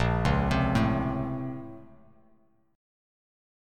A#mM9 Chord